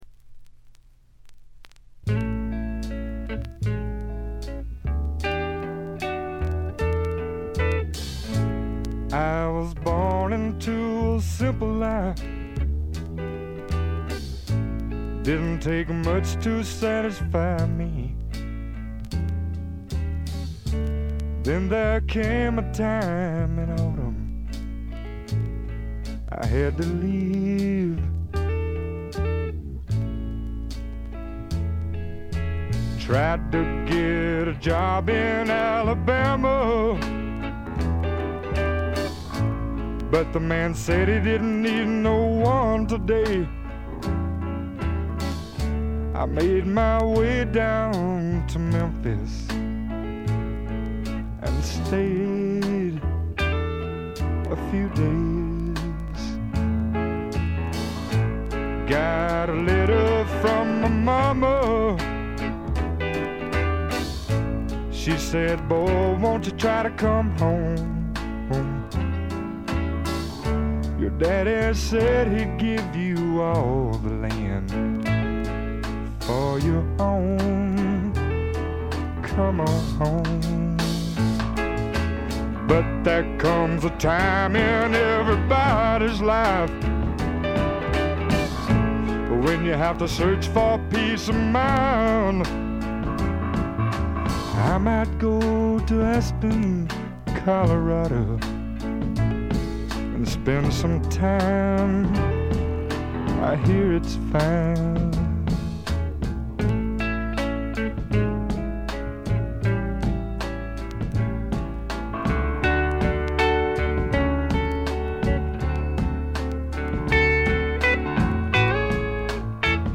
見た目よりよくなくて、バックグラウンドノイズ、チリプチ多め大きめ。
試聴曲は現品からの取り込み音源です。